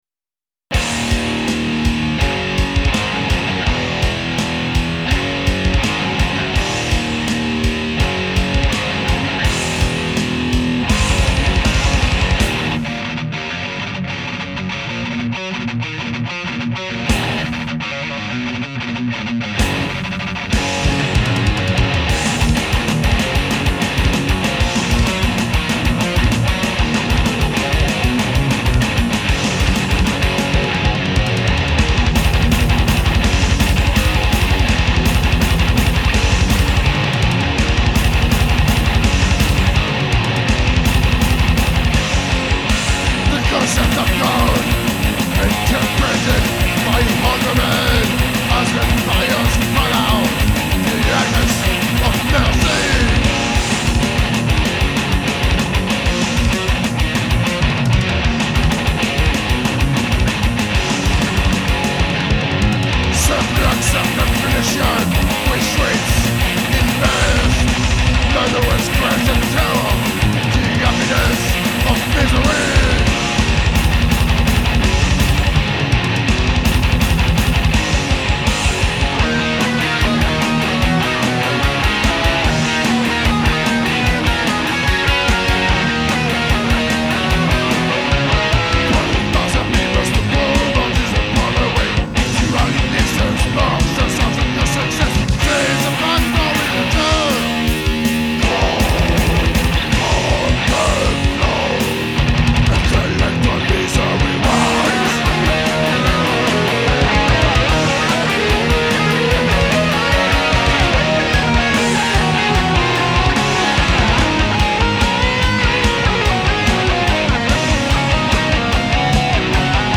---  THRASH-METAL GAULOIS - UN PROJET NÉ À SAMAROBRIVA ---